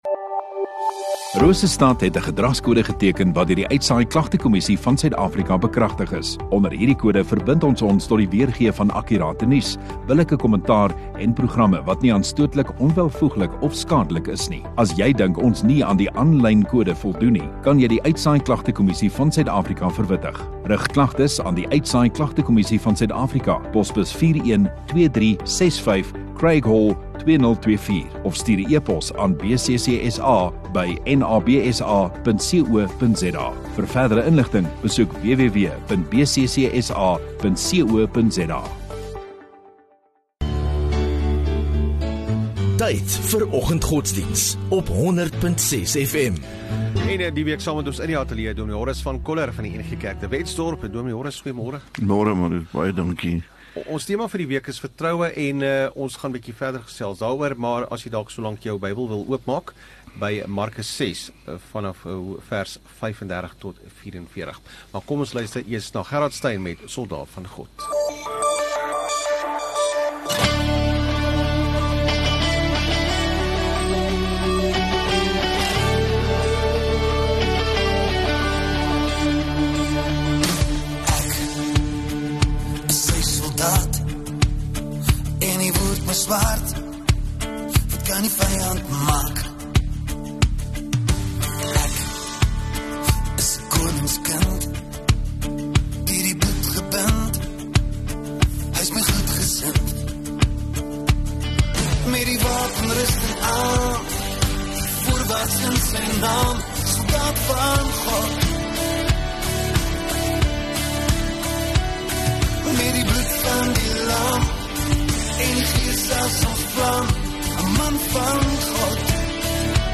20 Nov Woensdag Oggenddiens